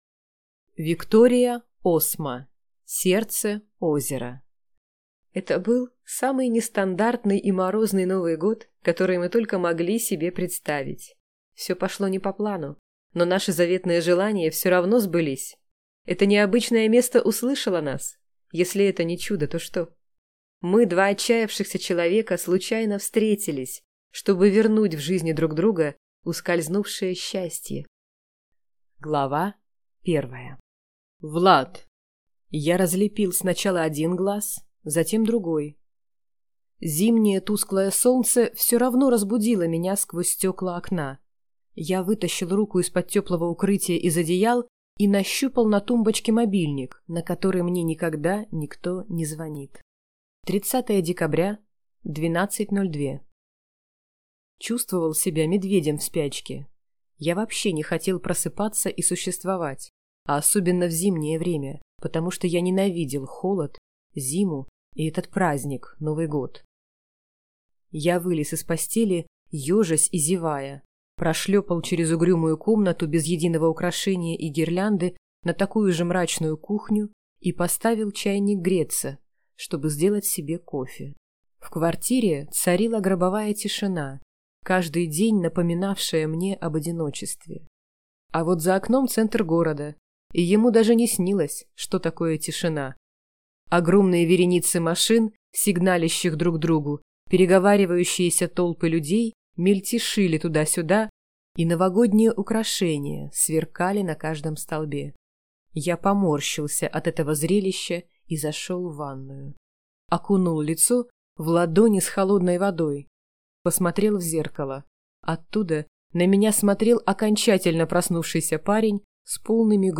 Аудиокнига Сердце-озеро | Библиотека аудиокниг
Прослушать и бесплатно скачать фрагмент аудиокниги